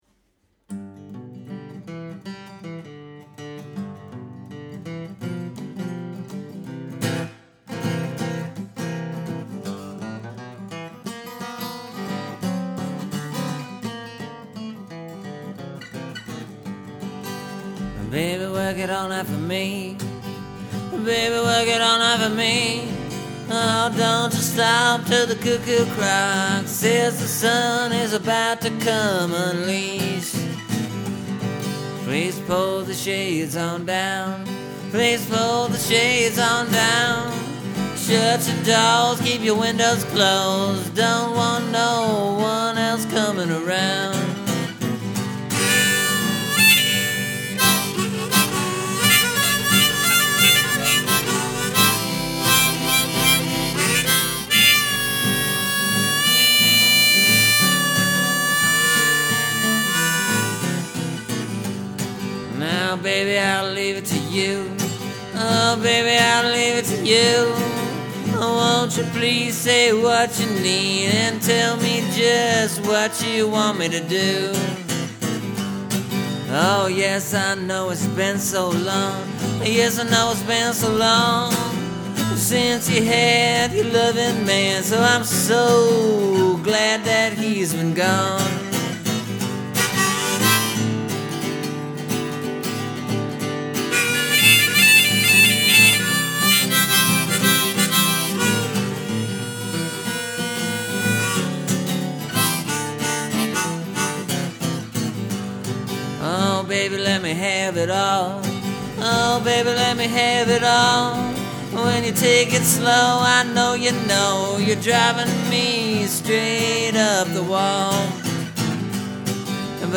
It’s a standard blues tune based on Blind Boy Fuller’sStep It Up and Go.”
I still wrote all the words and the guitar parts are my own (they’re not exactly like Blind Boy Fuller, because, well, I ain’t that good at guitar).